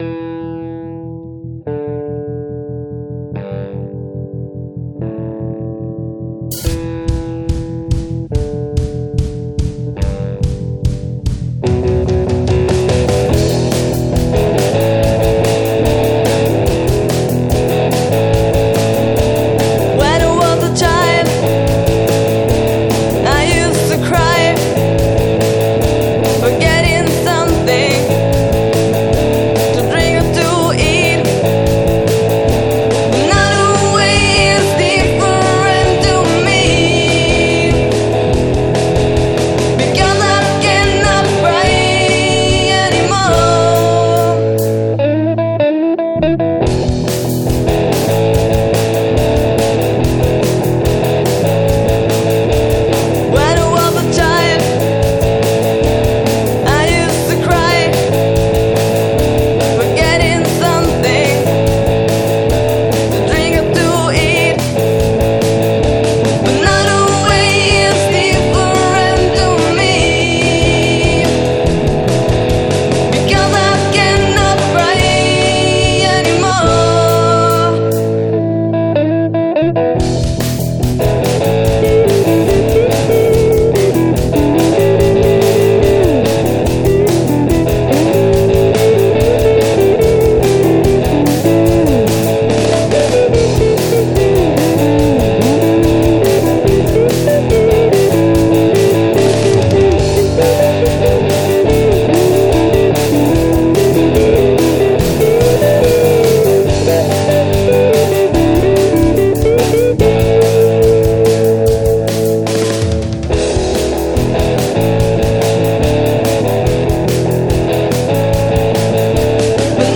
Genere: Rock & Roll
Chitarra
Basso
Voce
Batteria